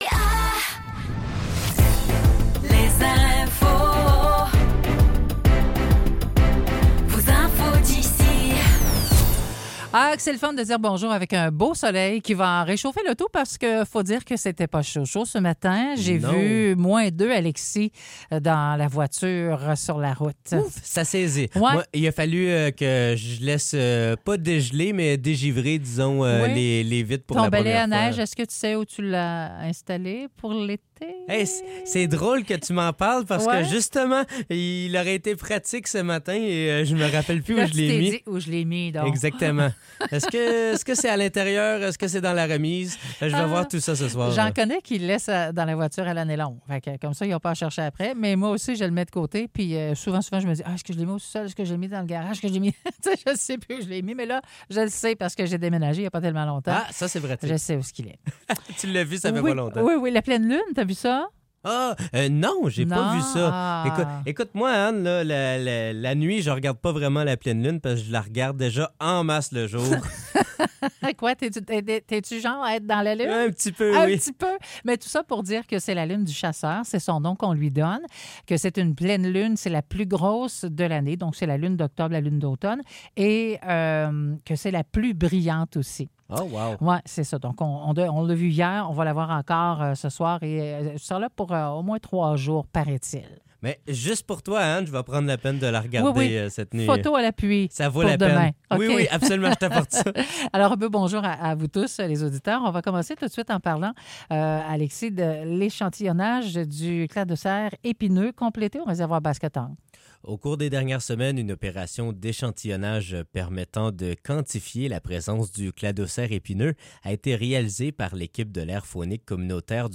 Nouvelles locales - 17 octobre 2024 - 9 h